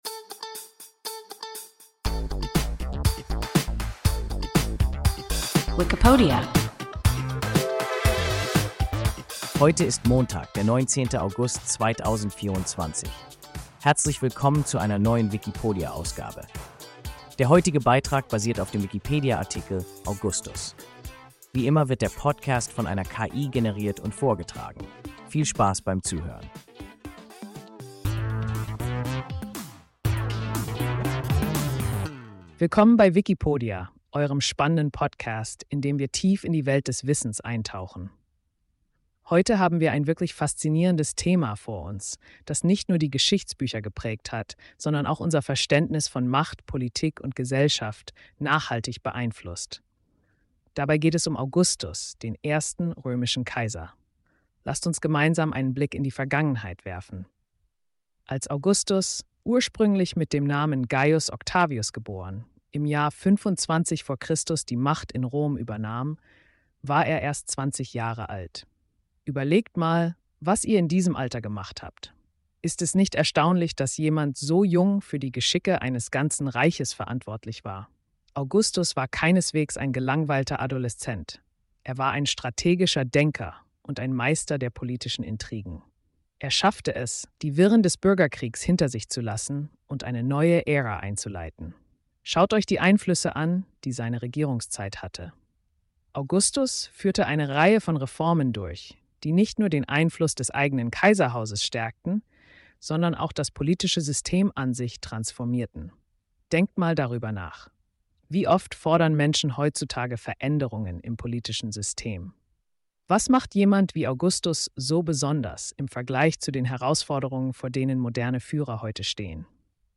Augustus – WIKIPODIA – ein KI Podcast